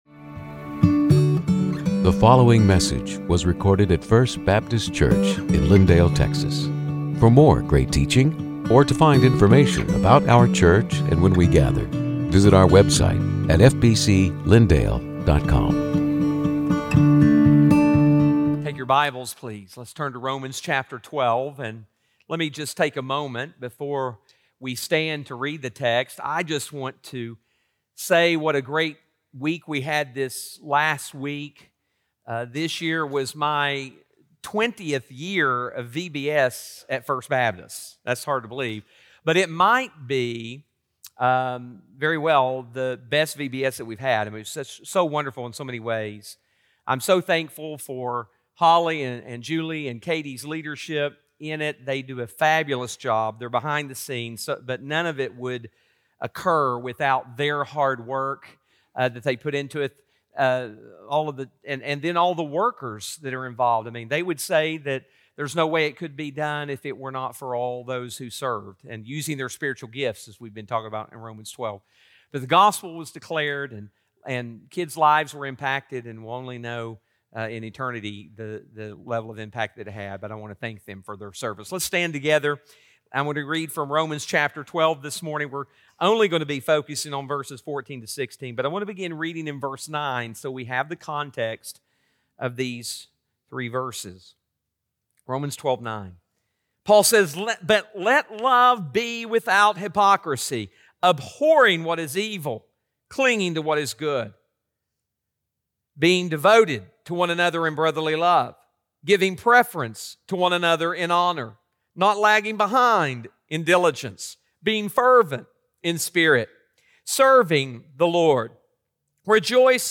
Sermons › Romans 12:14-16